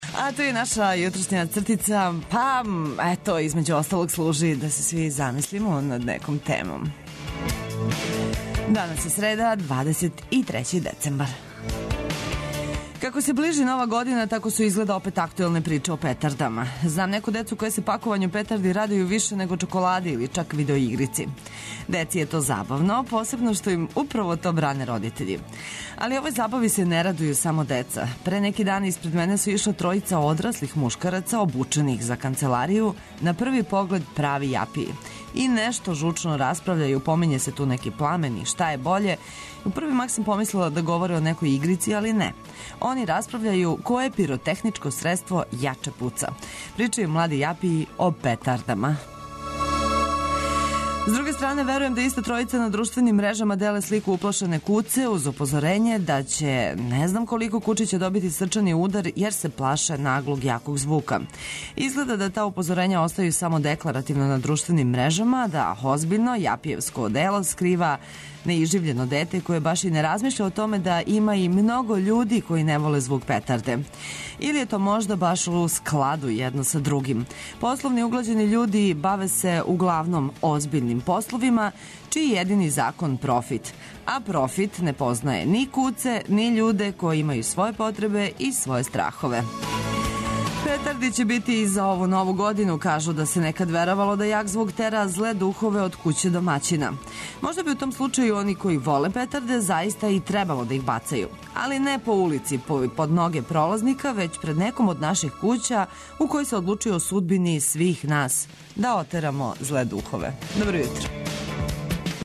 Будимо се и загревамо за нови дан уз добру музику, корисне информације и помало шале.